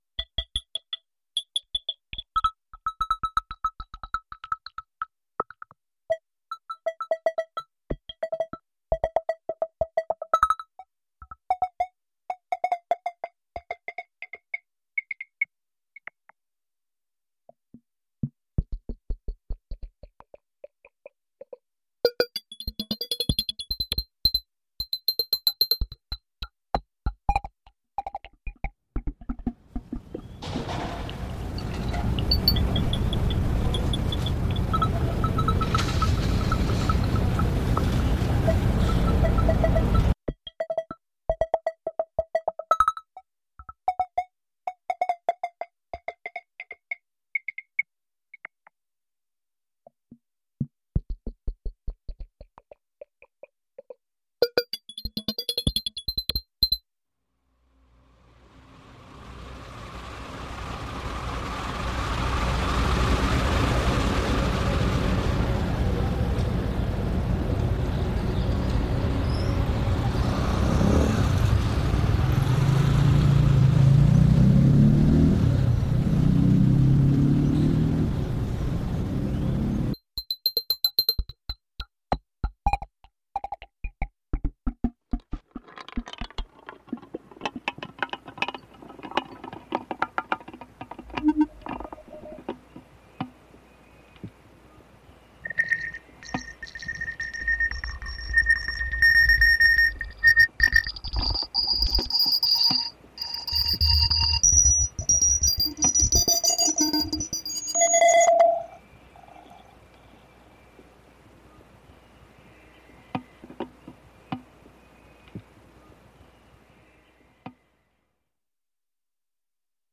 Sociedad Portuaria de Cartagena de Indias, Colombia.